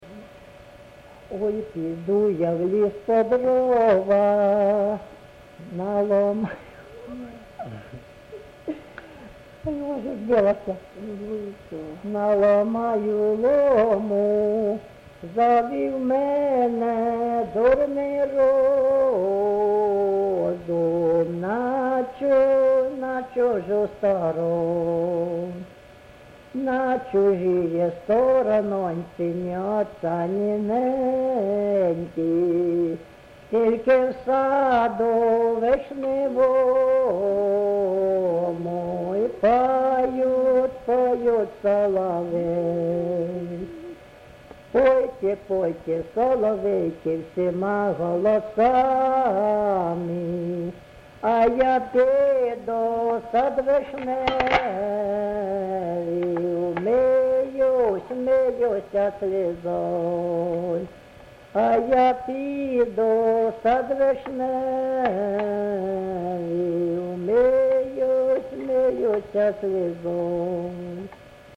ЖанрПісні з особистого та родинного життя
МотивЧужина, Журба, туга
Місце записум. Антрацит, Ровеньківський район, Луганська обл., Україна, Слобожанщина